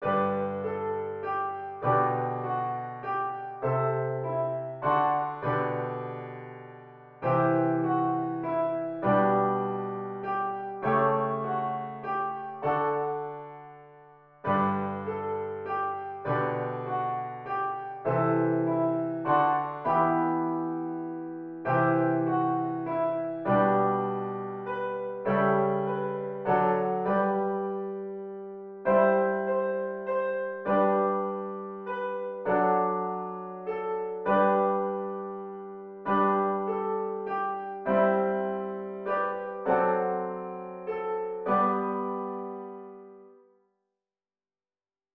Lower key
Psalm-73-Audio-Lower.wav